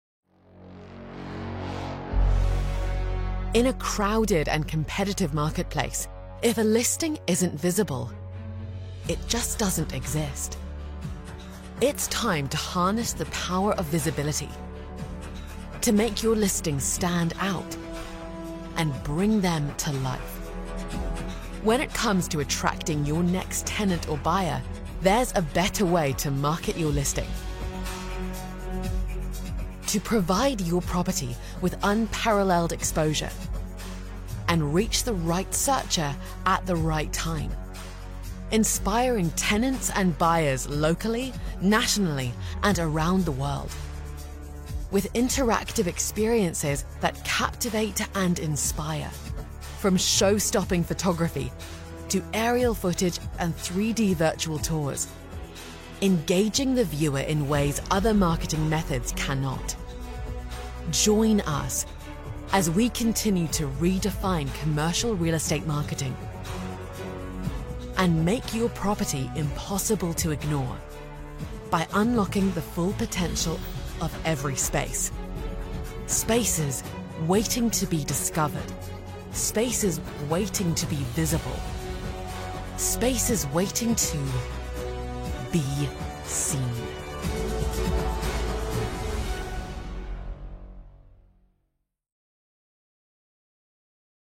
Inglês (britânico)
Inglês (Nova Zelândia)
Acessível
Articular
Conversacional